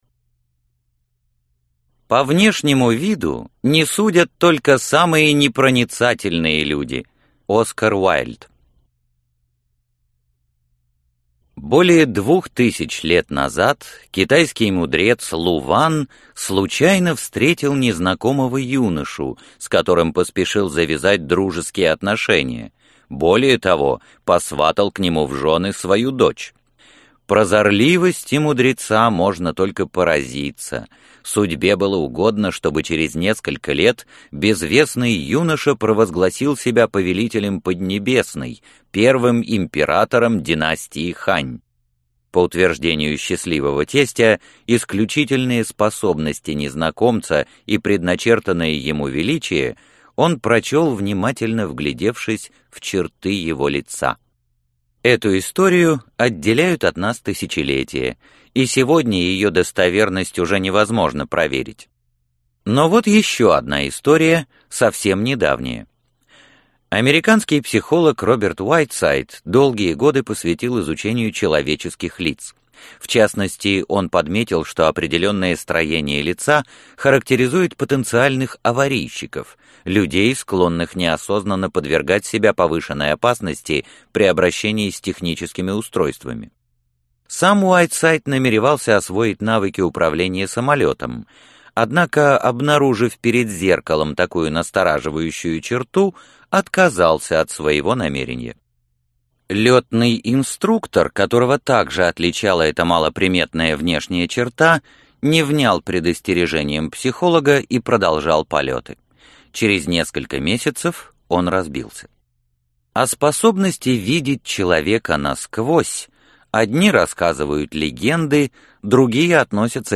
Аудиокнига Искусство видеть людей насквозь | Библиотека аудиокниг